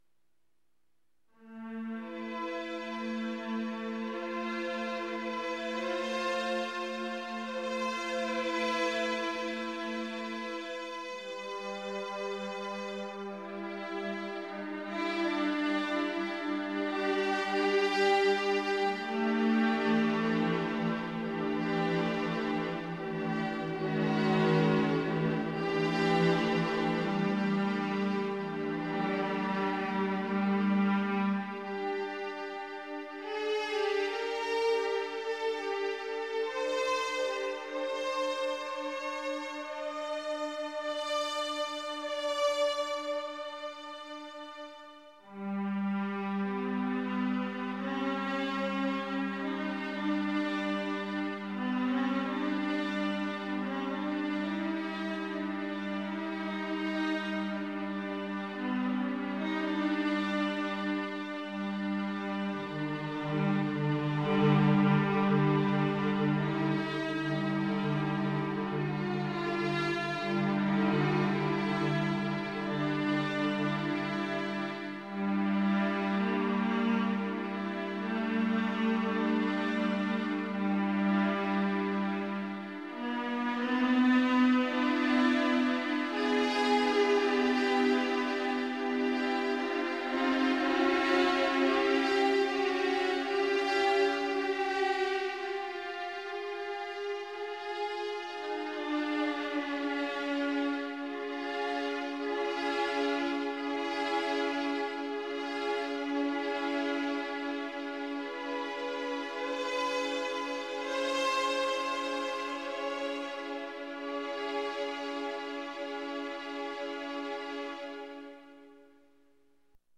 è stato uno dei pionieri della musica microtonale